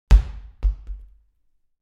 دانلود آهنگ تصادف 40 از افکت صوتی حمل و نقل
دانلود صدای تصادف 40 از ساعد نیوز با لینک مستقیم و کیفیت بالا
جلوه های صوتی